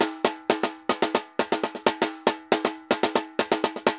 Tambor_Salsa 120_2.wav